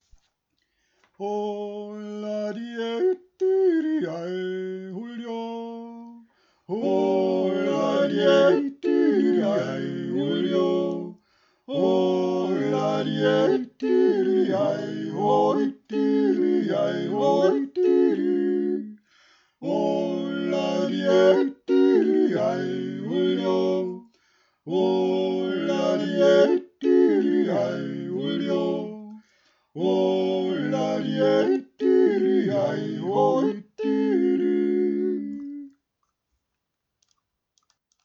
Dreistimmig